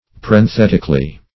parenthetically - definition of parenthetically - synonyms, pronunciation, spelling from Free Dictionary
Parenthetically \Par`en*thet"ic*al*ly\, adv.